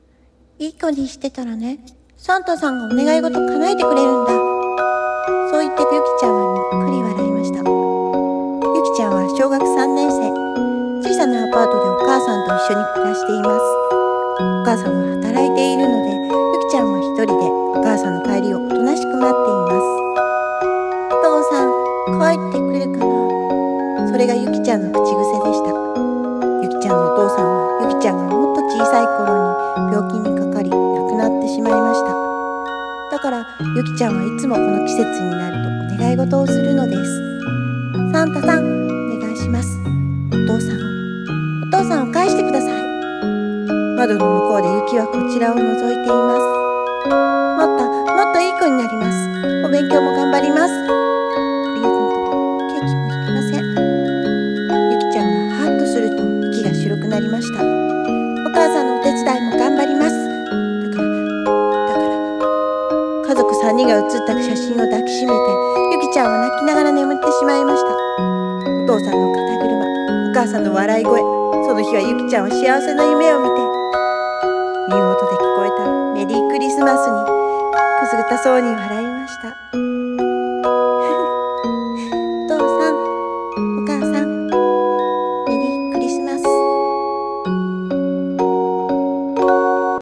】【1人声劇】ゆきちゃんのクリスマス 読み手様 。